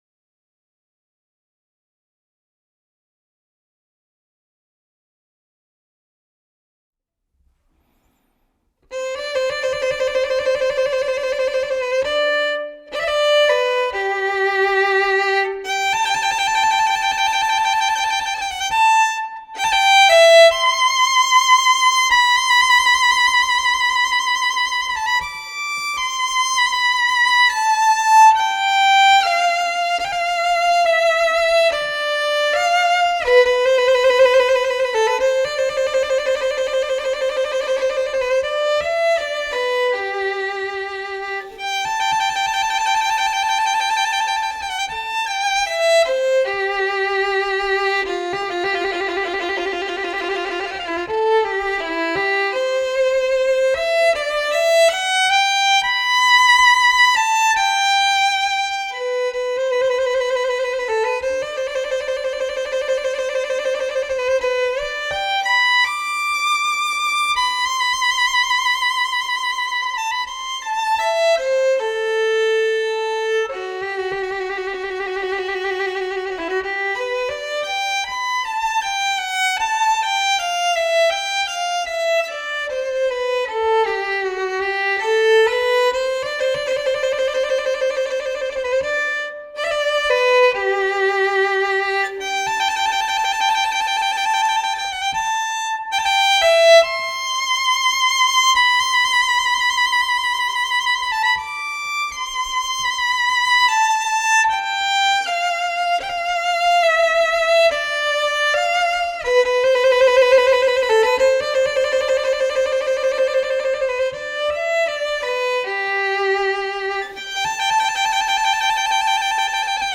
Una selección de estudios del libro 60 estudios para violín op. 45 del violinista y pedagogo alemán F. Wolfhart, en la edición de Frigyes Sándor.